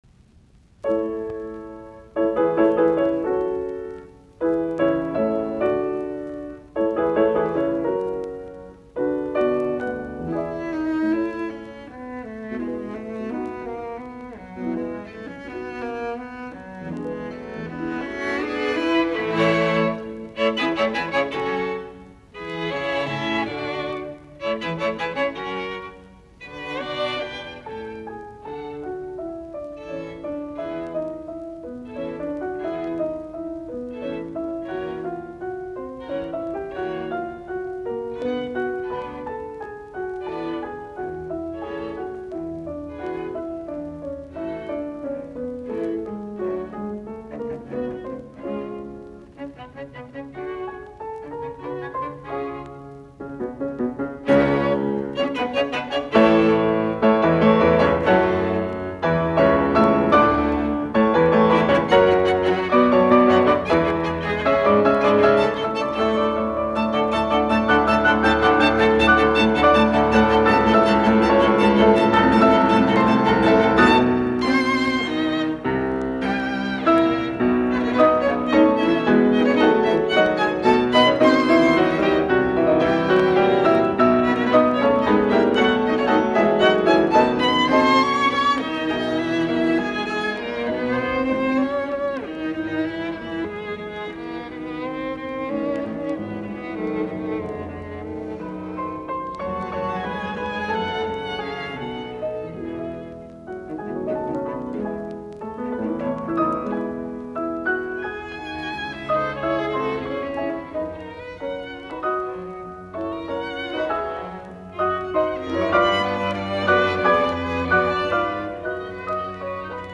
Quartet No. 2 in A Major for Piano and Strings, Op. 26
Allegro non troppo SIB_LP00089_A_01.mp3 - 11.25 MB 2.